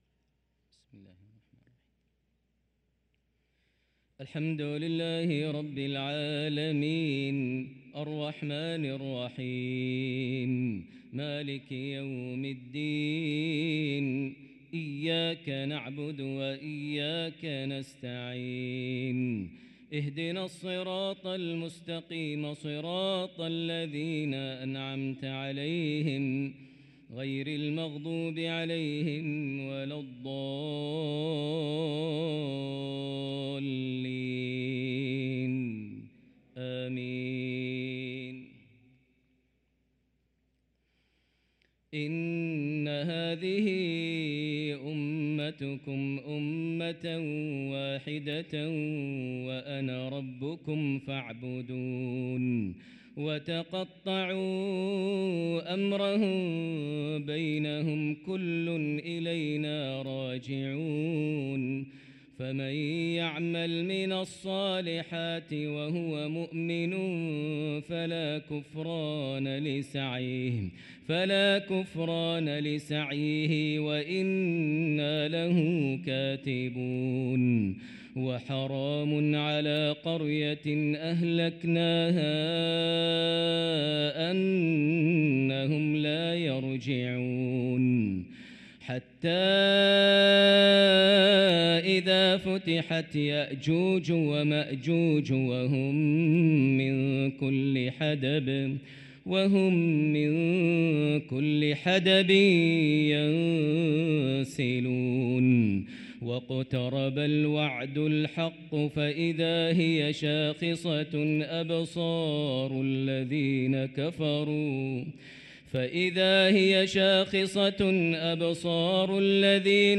صلاة العشاء للقارئ ماهر المعيقلي 16 ربيع الآخر 1445 هـ
تِلَاوَات الْحَرَمَيْن .